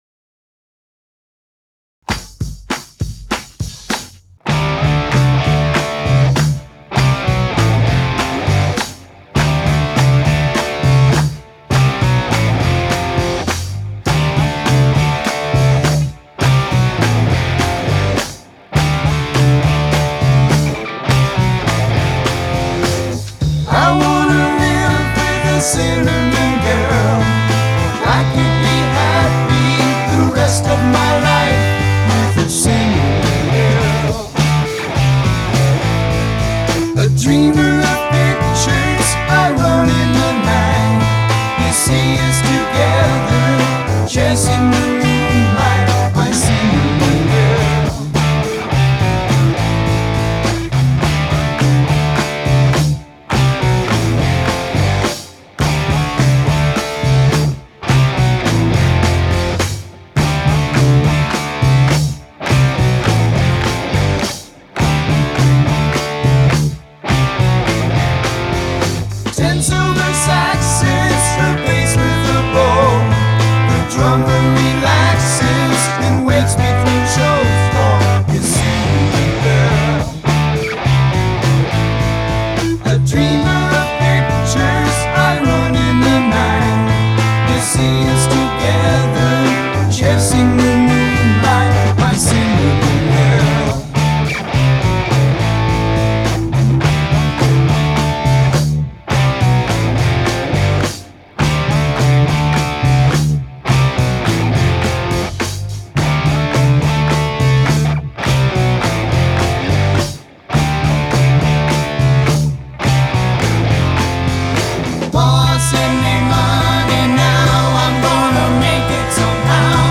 Great tone and great playing!